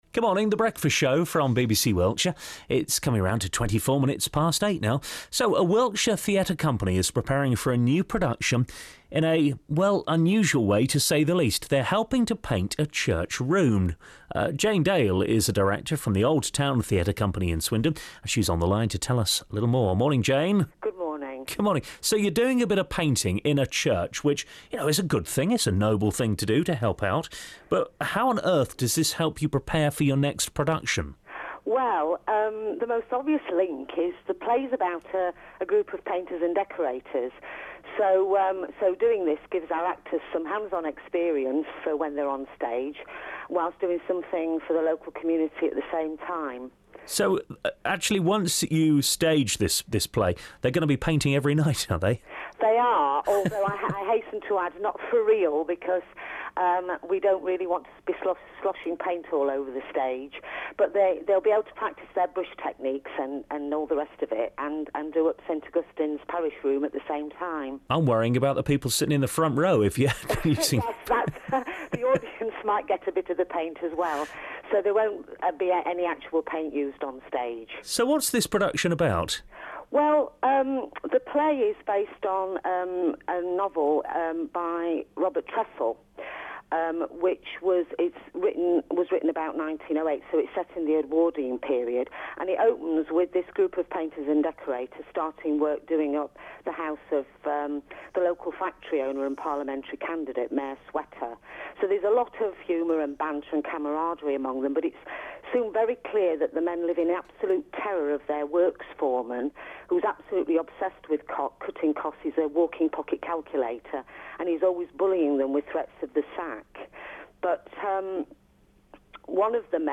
BBC Radio Swindon Interview